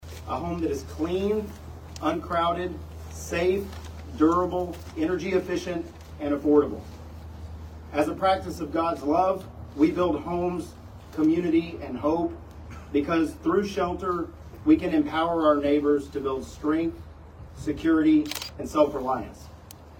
Manhattan Area Habitat for Humanity holds ribbon cutting ceremony for local family